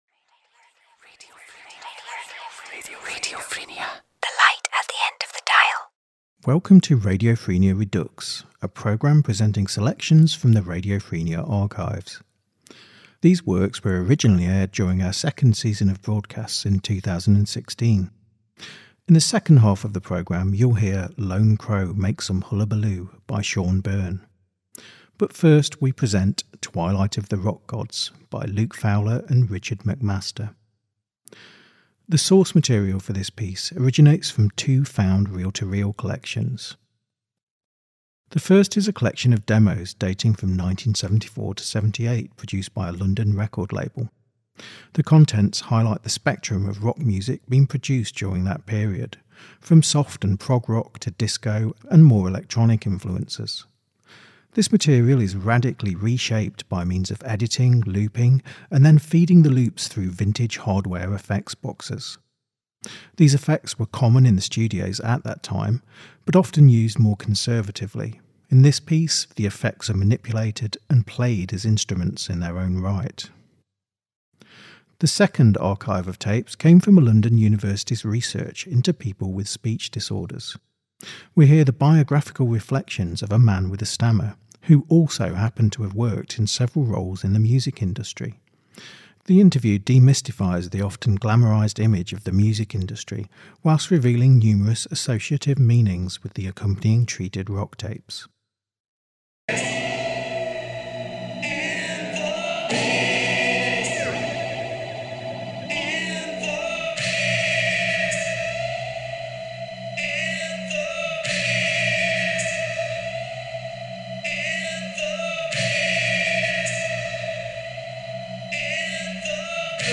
The source material originates from two found reel to reel collections; one is a collection of record label demos from 1974-1978, the other an archive of a university’s research into speech disorders which includes an interview with a man who worked in the music industry. Excerpts from these tapes are manipulated and radically reshaped by feeding the raw material through a series of vintage effects boxes relevant to the era in which the original recordings were made.
This work blends and processes poetry with soundscape, loosely based around a recurring dream of an intense relationship with a trickster crow and memories of a childhood before adolescent psychiatric wards.